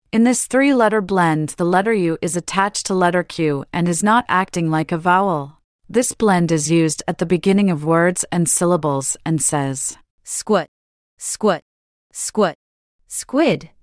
This blend is used at the beginning of words and syllables and says: /skw/, /skw/, /skw/, squid.
/skw/
SQU-squid-lesson.mp3